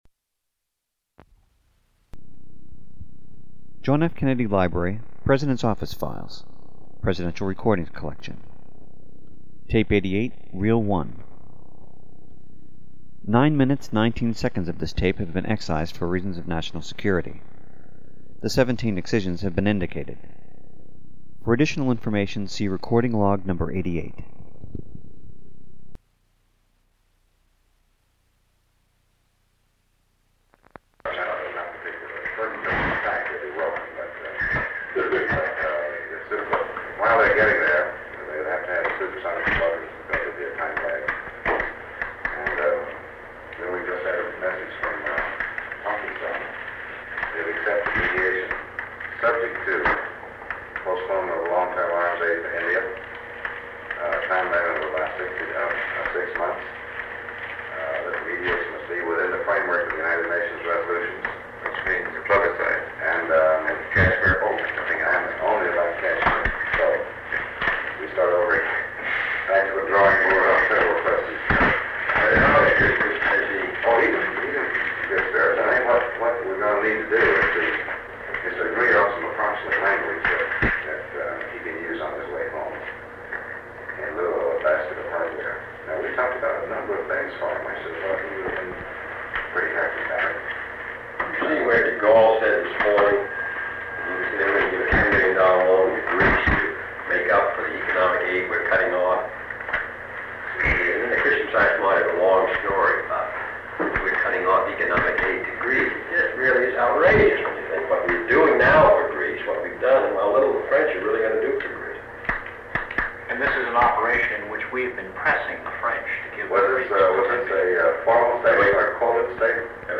Arms Limitation/Israel, 17 May 1963 Phillips Talbot James Grant Robert W. Komer John F. Kennedy Dean Rusk McGeorge "Mac" Bundy Middle East American Defense and Security Asia War and Terrorism Sound recording of a meeting most likely held on May 17, 1963, between President John F. Kennedy, Secretary of State Dean Rusk, Assistant Secretary of State for Near Eastern and South Asian Affairs Philips Talbot, Deputy Assistant Secretary of State for Near Eastern and South Asian Affairs James P. Grant, Special Assistant to the President for National Security Affairs McGeorge Bundy, and member of the National Security Council Robert W. Komer. They discuss the Middle East, specifically the hope that an arms limitation agreement can be coordinated for that area of the world.
The goal of the United States is an arms limitation agreement with inspections, and this meeting provides an overview of the steps the Kennedy Administration wants to take to aid in the peace of the region. Eight segments of the recording totaling 5 minutes and 10 seconds have been removed in accordance with Section 3.4 (b) (1), (3) of Executive Order 12958.